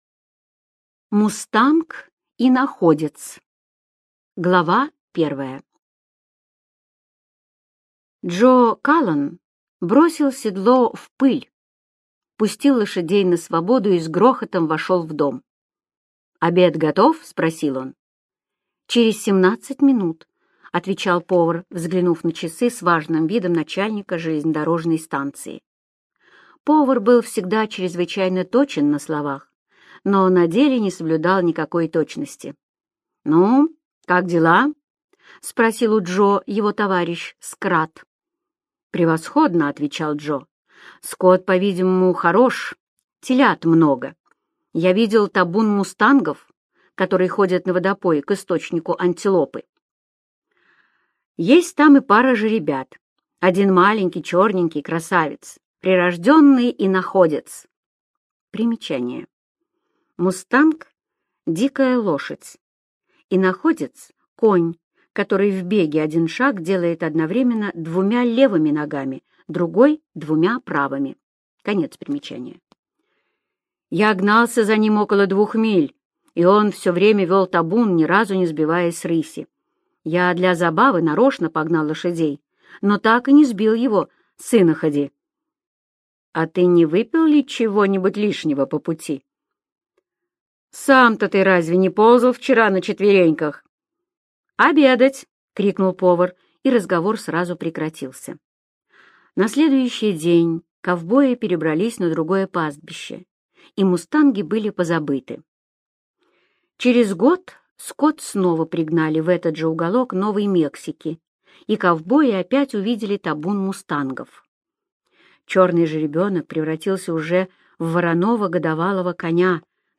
Мустанг-иноходец - аудио рассказ Эрнеста Сетона-Томпсона - слушать онлайн